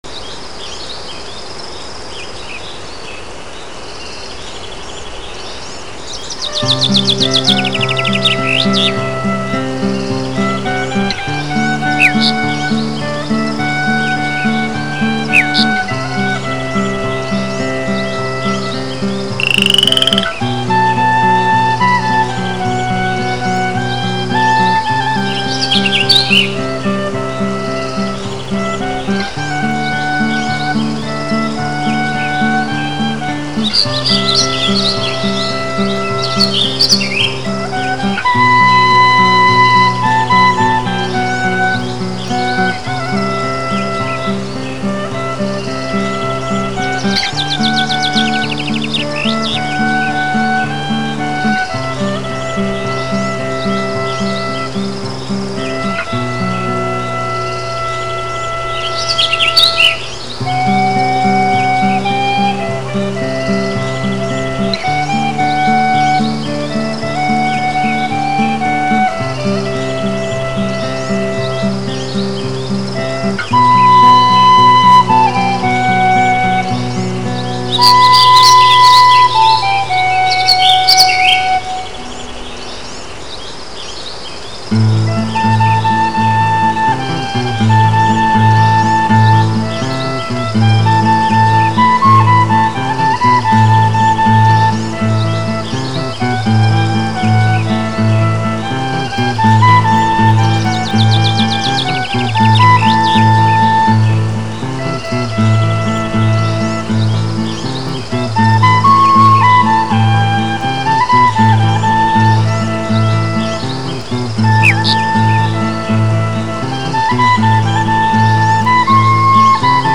Звуки здесь естественны, как сама Природа.
Genre: Medieval Folk / Ambient